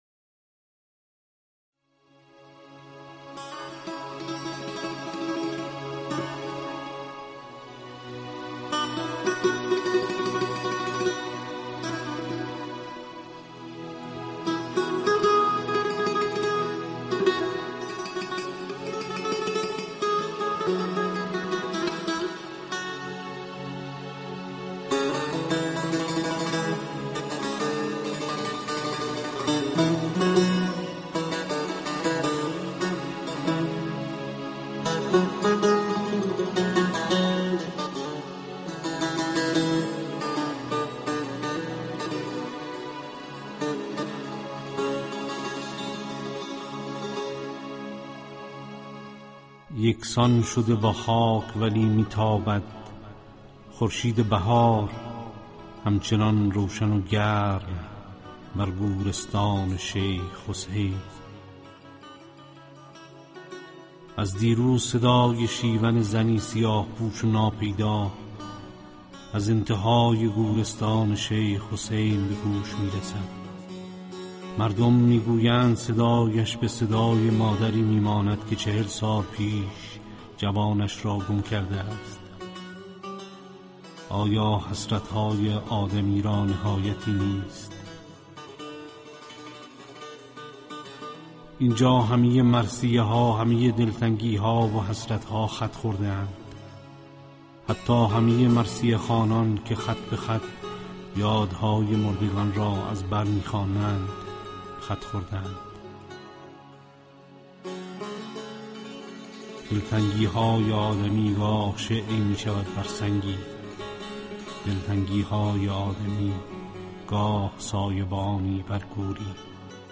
بازخوانی